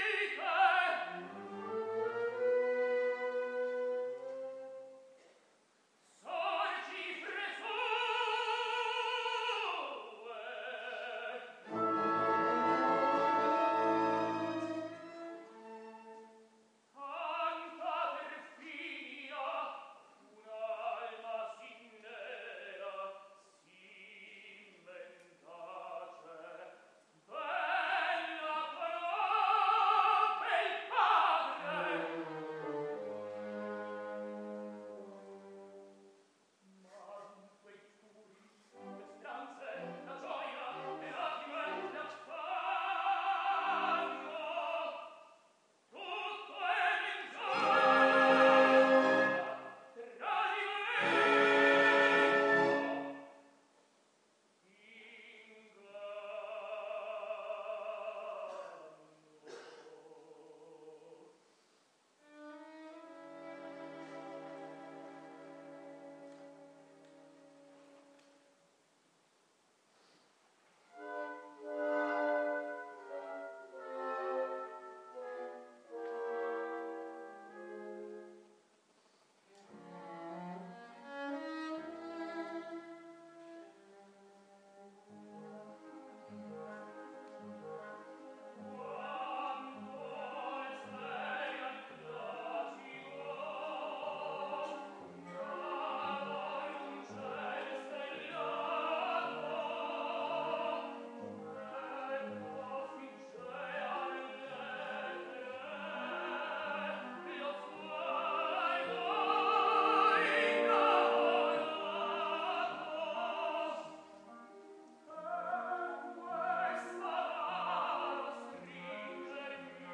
To celebrate the 200 years since the birth of Giuseppe Verdi, the Auditorium at the Ciocco hotel was standing room only this evening as more than 900 guests enjoyed the Annual Charity New Years Concert with all proceeds going to Amatafrica and their mission in Muhura, Rwanda.